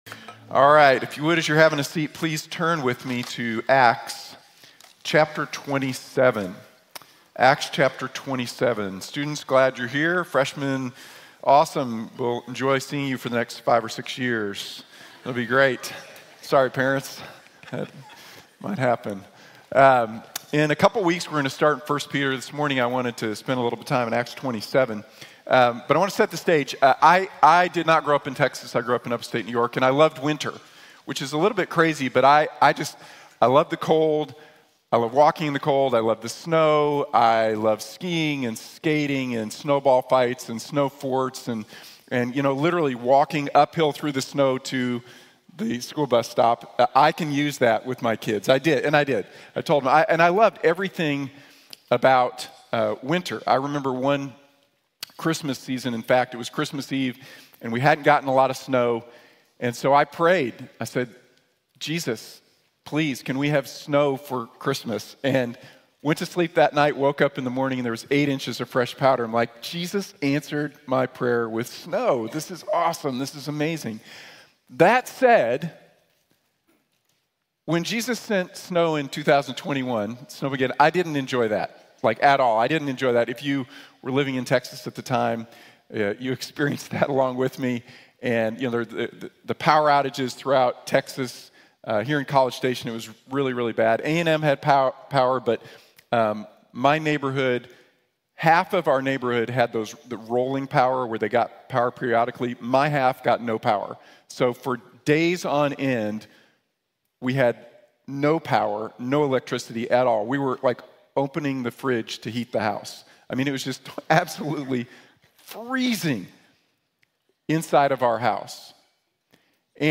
Lessons from the Storm | Sermon | Grace Bible Church